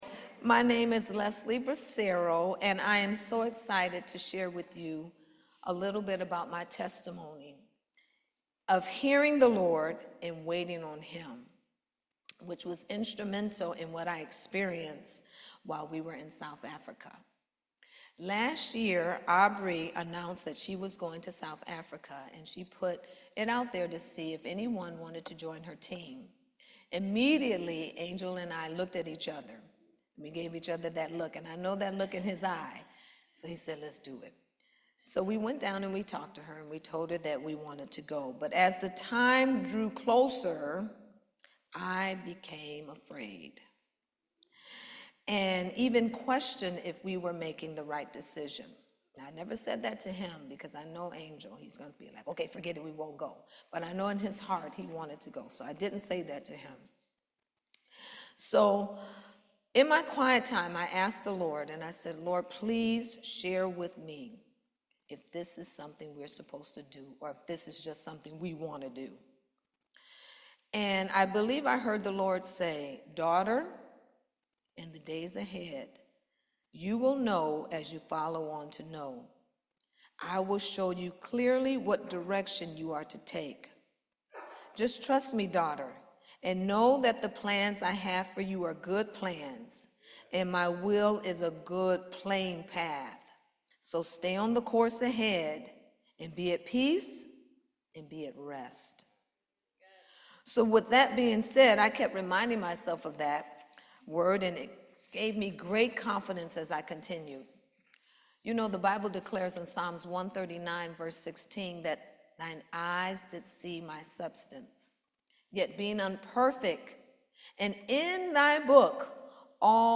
Genesis 22:1-24 Service Type: Main Service Waiting is an act of being.